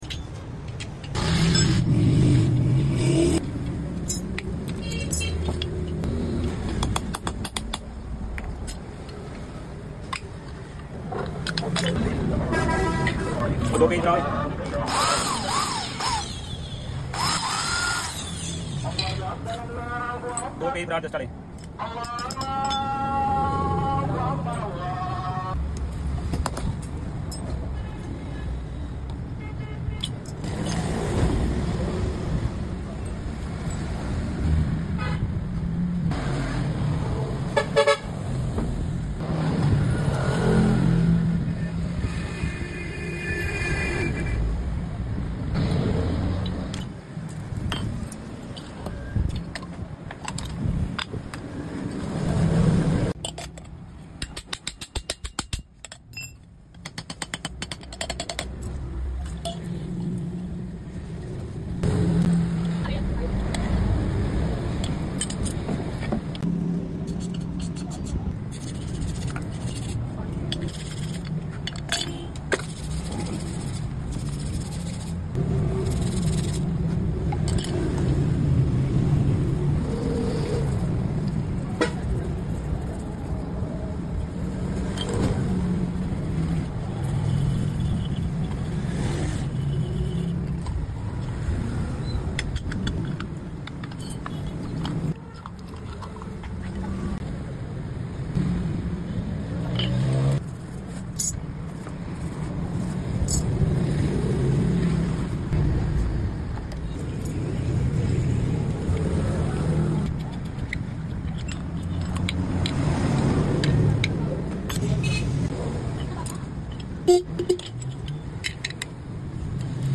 Dead bike horn fully restored sound effects free download